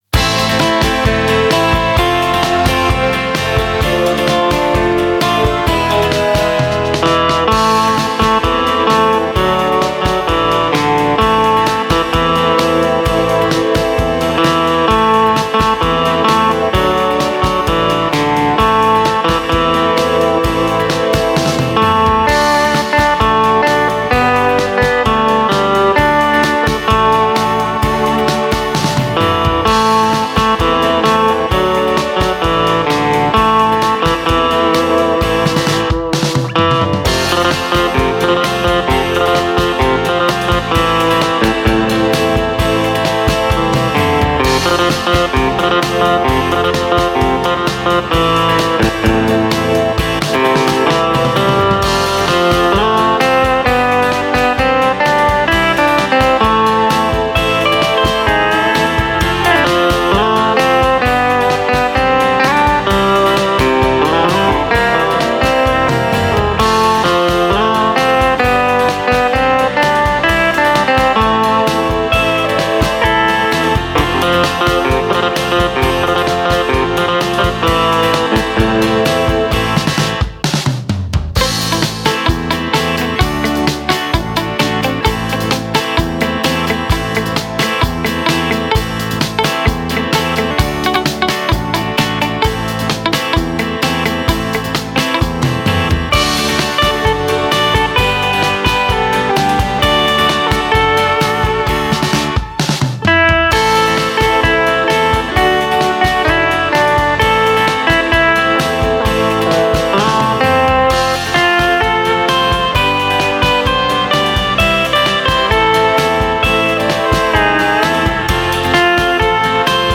(INGEN former for overspil/trickindspilninger.)